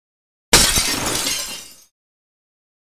Glass breaking
Category: Sound FX   Right: Personal